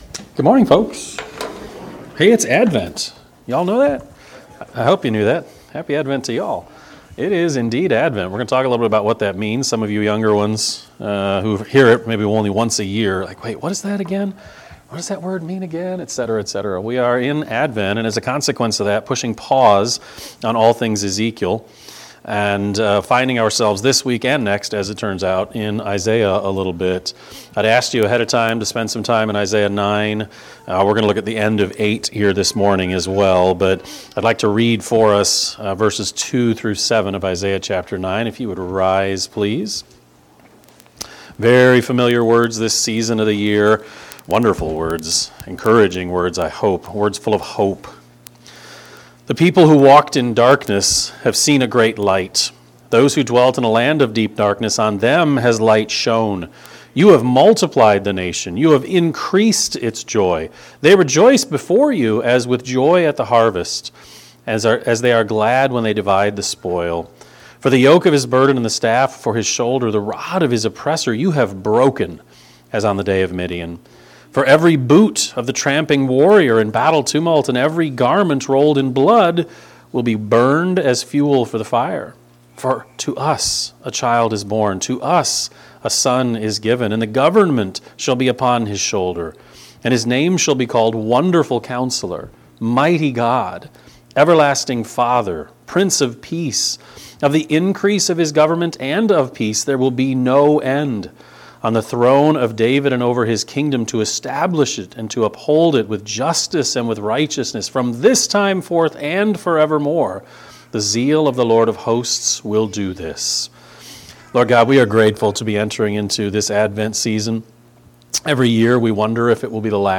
Sermon-12-1-24-Edit.mp3